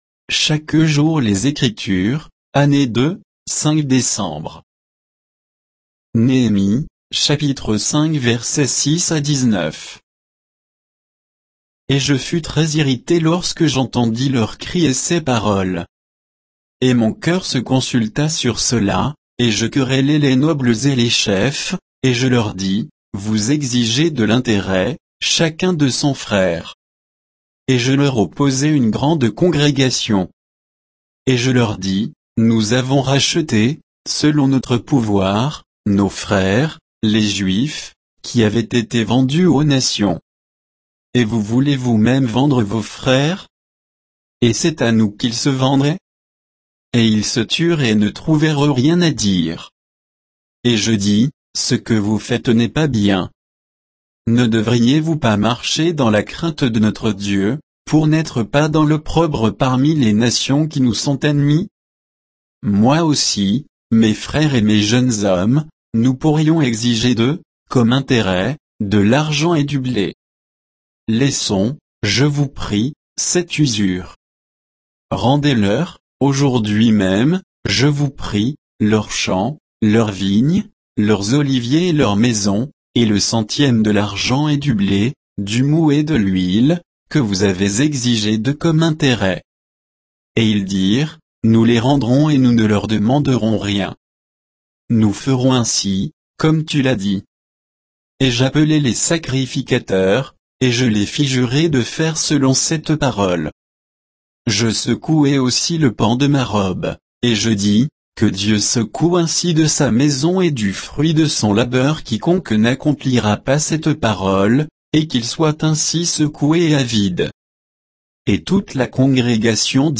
Méditation quoditienne de Chaque jour les Écritures sur Néhémie 5, 6 à 19